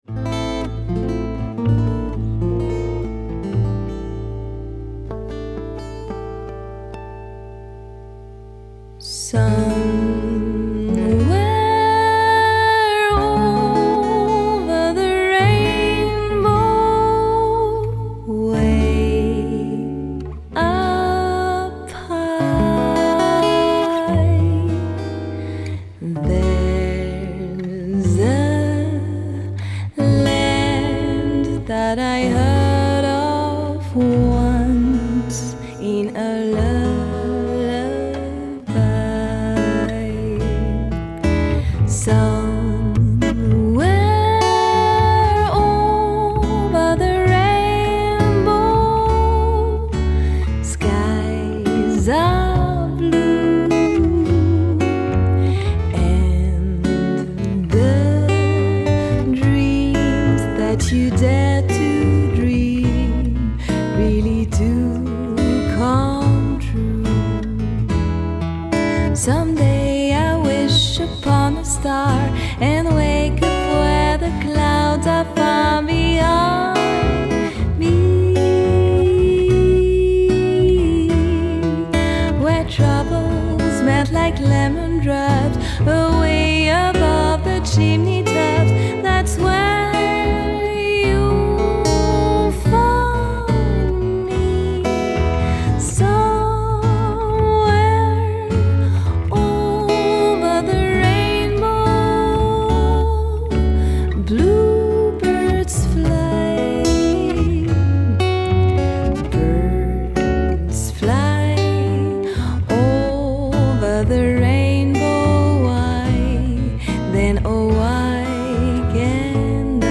démo chant